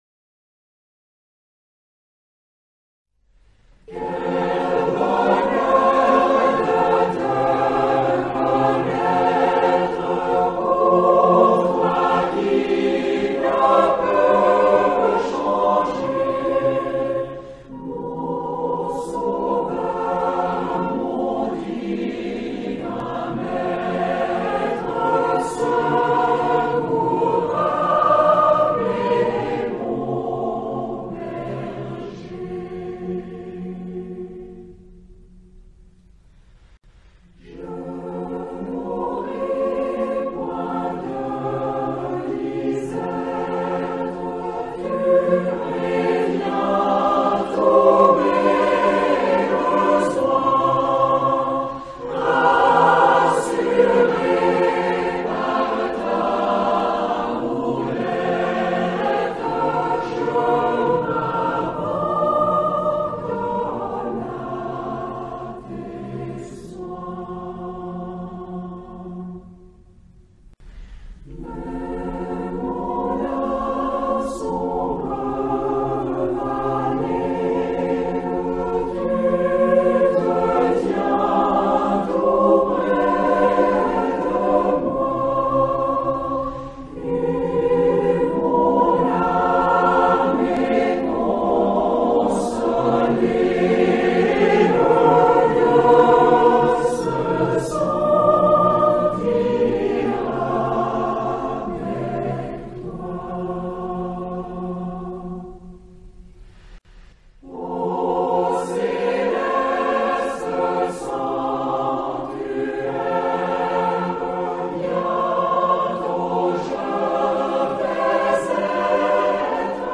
Enregistrement : Lausanne